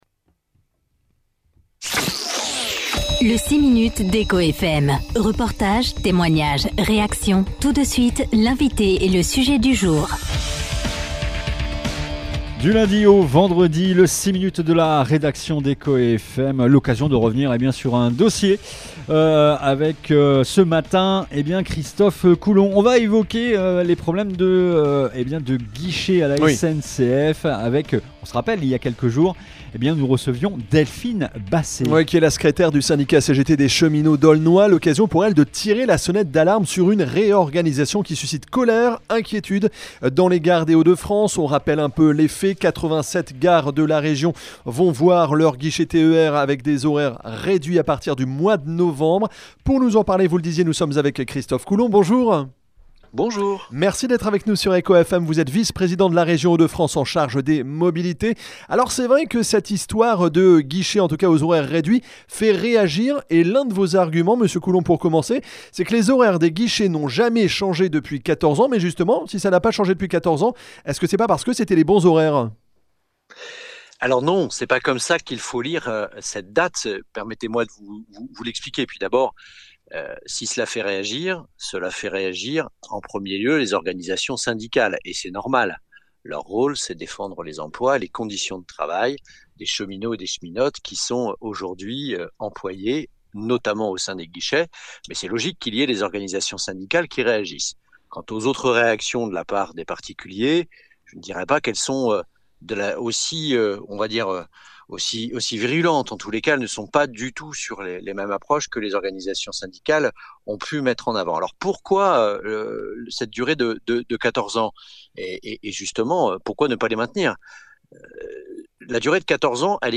Ce mercredi 24 septembre, Christophe Coulon, vice-président de la région Hauts-de-France en charge des mobilités, était l’invité du 6 minutes d’Echo FM.